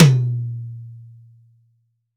Index of /90_sSampleCDs/AKAI S6000 CD-ROM - Volume 3/Drum_Kit/ROCK_KIT1
MIX2 HTOM -S.WAV